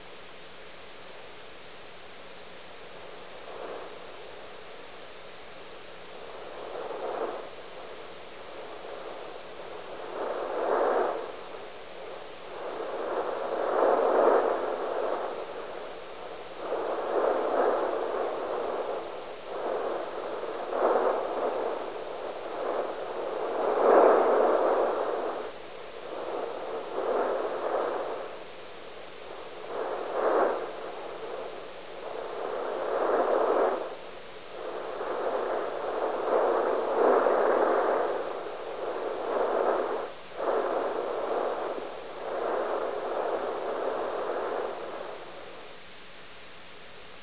Daha sonra tekrar dinlemek veya üzerinde çalışmak için Jüpiter’in seslerini kaydetmek isteyebilirsiniz.
Kayıt-1 (L-Bursts) ,
lbursts.wav